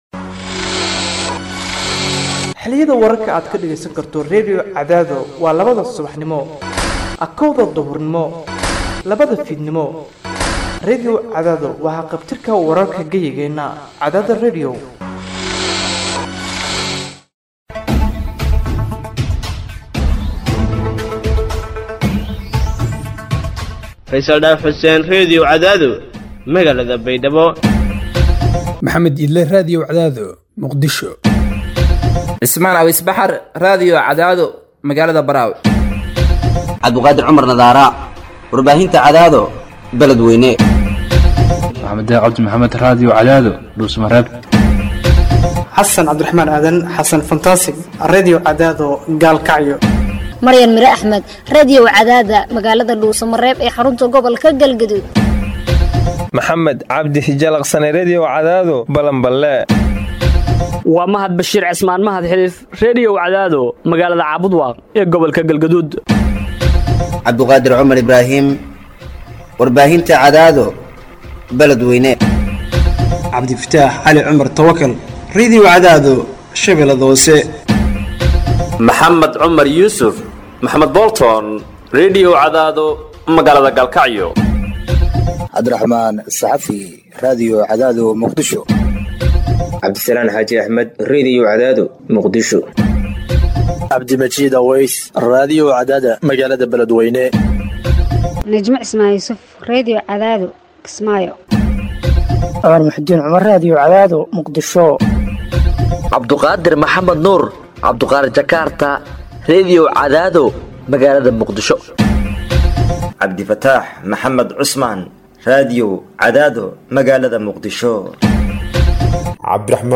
Hoos Ka Dhageyso Warka Duhur Ee Radio Cadaado